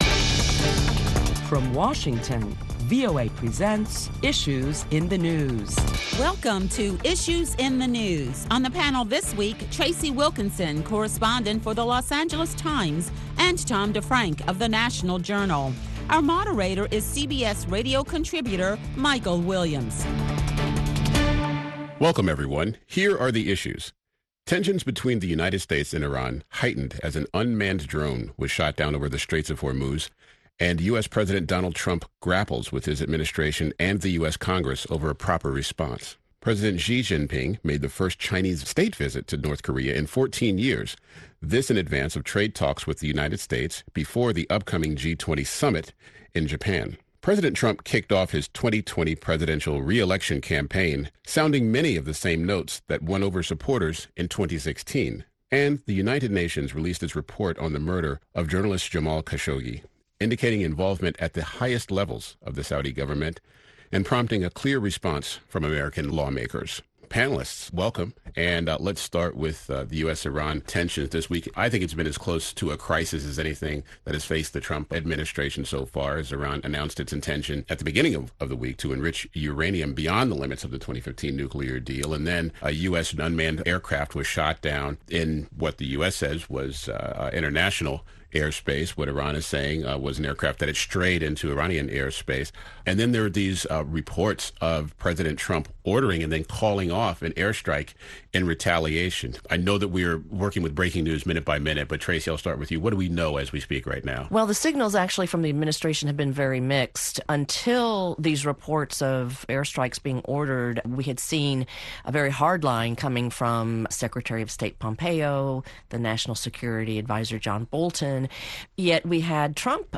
Listen to a panel of prominent Washington journalists as they deliberate the week's headlines including President Trump officially kicking off his 2020 election campaign at a rally in Florida and escalating tensions between Iran and the US.